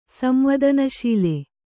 ♪ samvādana śile